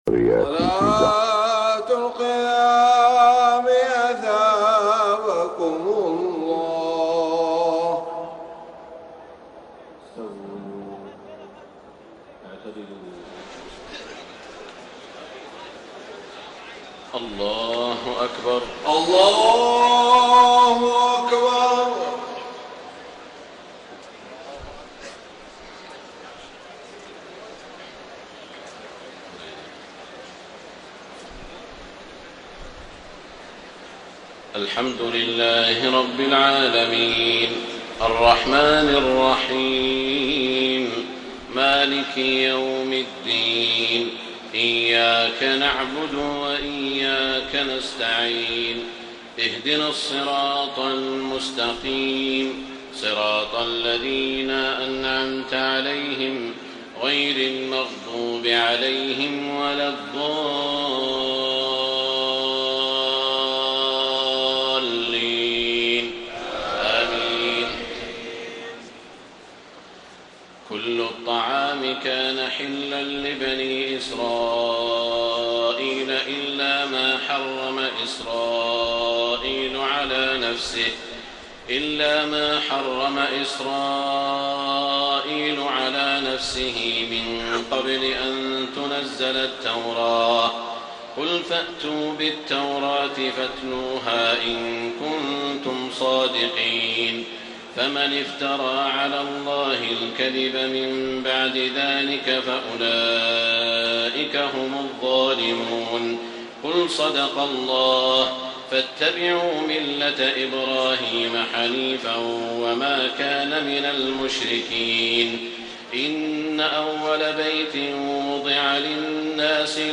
تهجد ليلة 24 رمضان 1433هـ من سورة آل عمران (93-185) Tahajjud 24 st night Ramadan 1433H from Surah Aal-i-Imraan > تراويح الحرم المكي عام 1433 🕋 > التراويح - تلاوات الحرمين